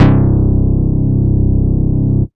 LOW135BASS-L.wav